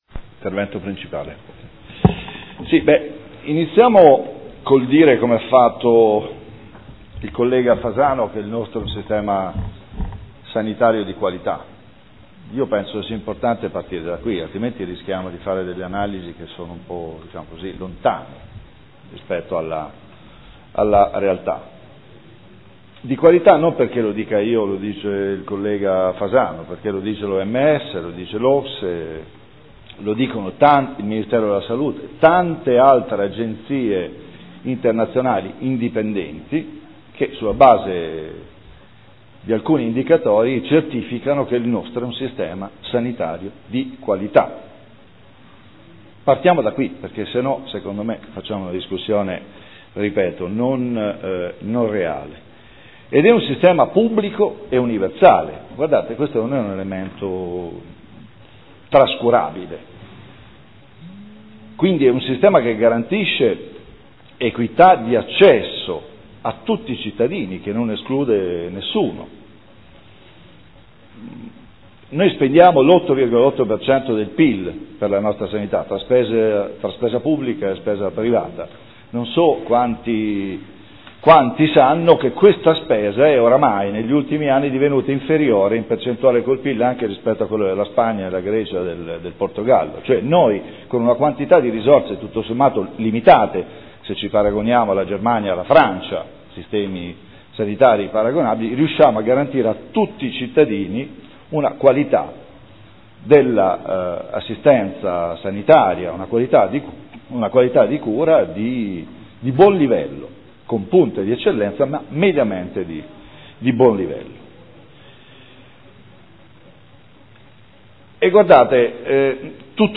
Seduta del 23/07/2015 Dibattito sulla Sanità